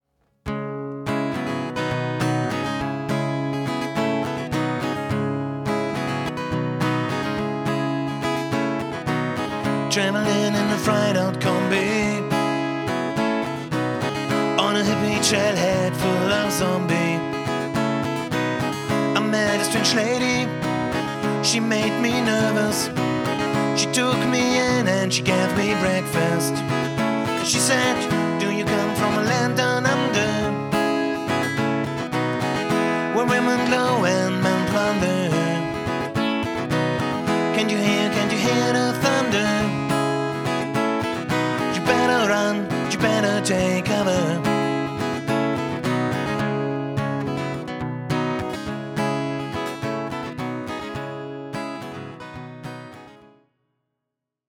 Rock & Pop Cover